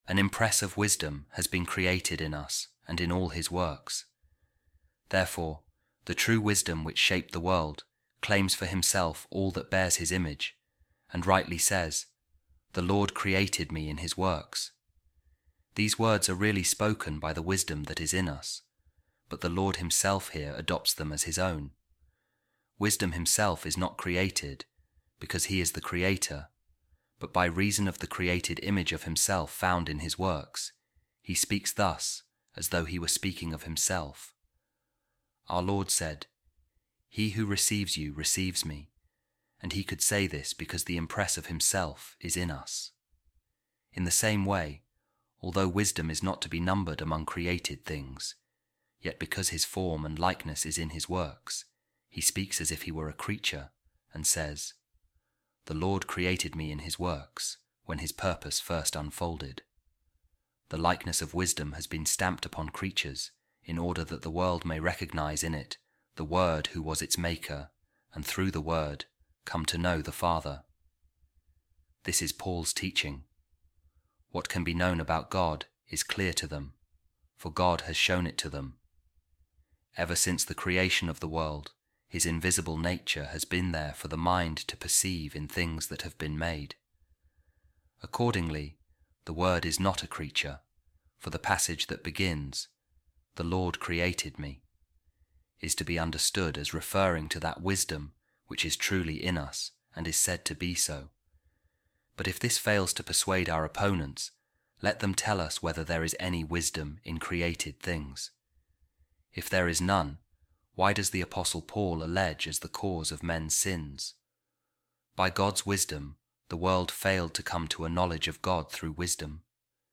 A Reading From The Discourses Of Saint Athanasius Against The Arians | Wisdom’s Likeness And Image Is In God’s Works